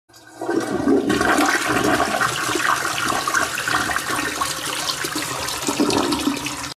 Звуки смыва унитаза
Шум сантехники в ванной